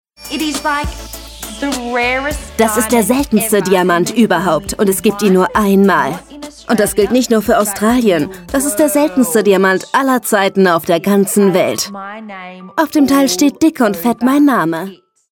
Sprecherin, Synchronsprecherin